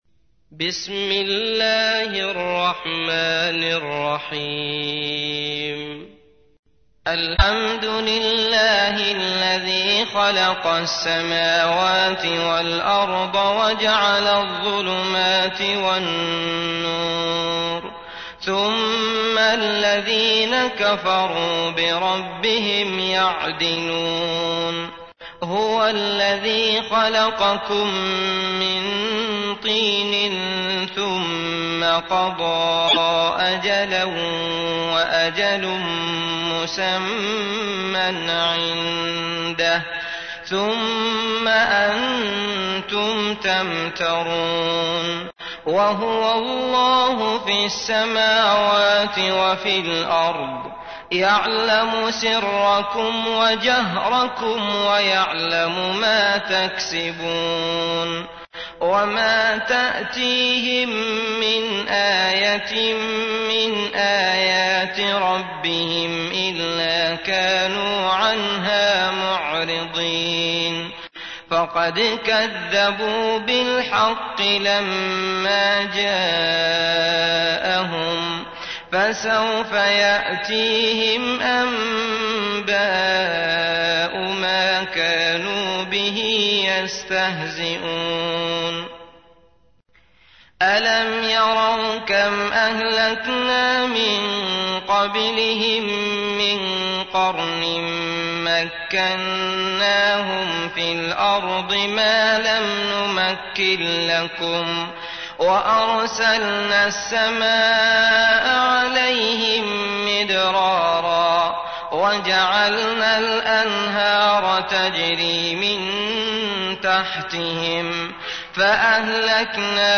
تحميل : 6. سورة الأنعام / القارئ عبد الله المطرود / القرآن الكريم / موقع يا حسين